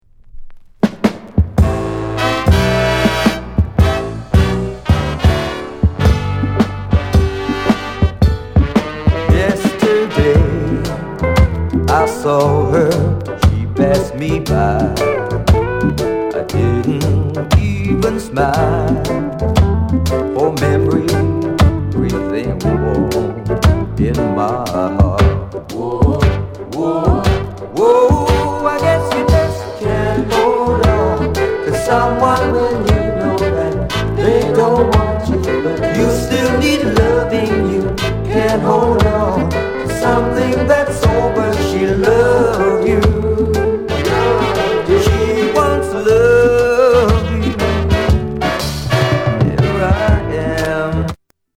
VG�� ������ RARE SOULFUL REGGAE